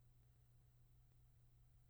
Special Interest Groups Audiobook Production
I’m attaching four files - two are of silence areas in the files (both original and working copy/cleaned up version) and two include a small bit of sound in roughly the same area in the recording.